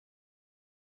wavinfo Test Project - Audio - Auro3d 10.0.wav